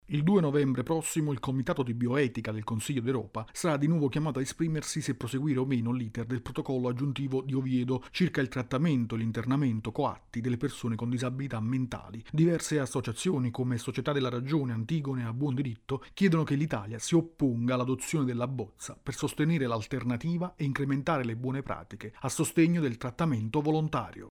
Basta coercizione: le associazioni fanno appello all’Italia contro l’internamento coatto delle persone con disabilità mentale contenute nel protocollo di Oviedo. Il servizio